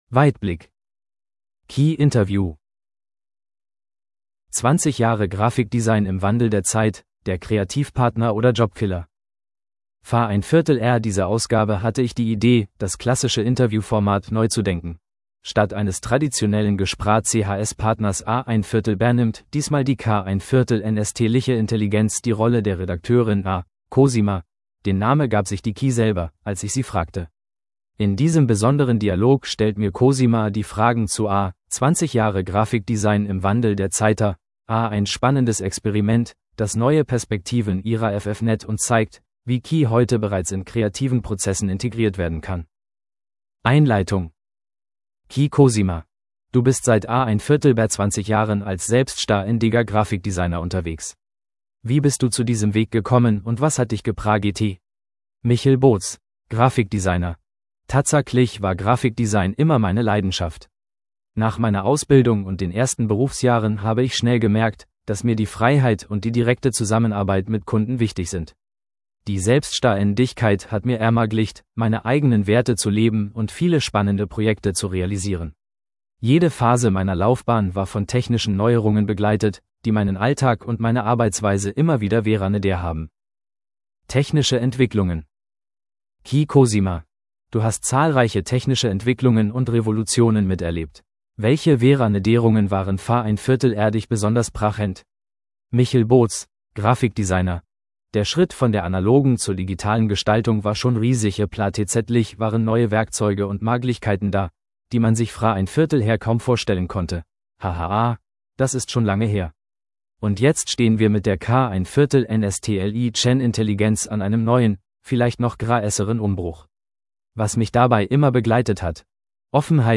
KI-Interview | B
ki-interview_de_5.mp3